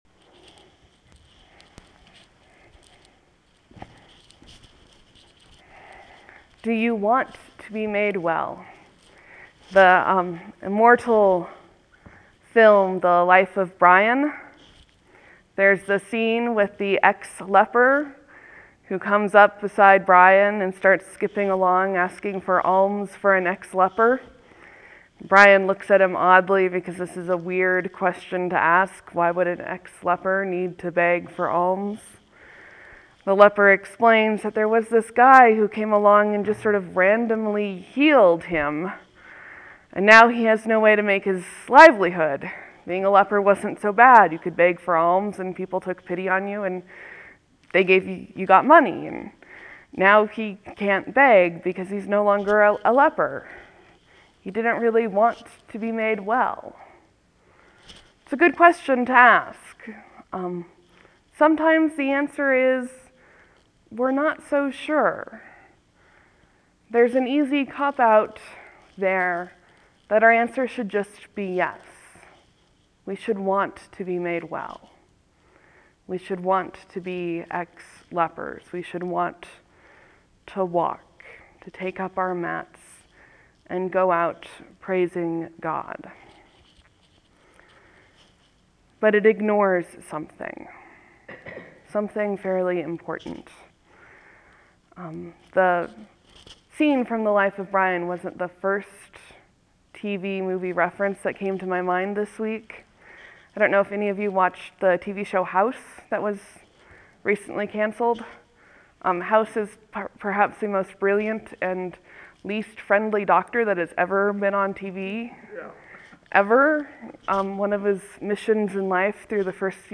Wellness and Fear, Sermon for Easter 6, 2013 (audio)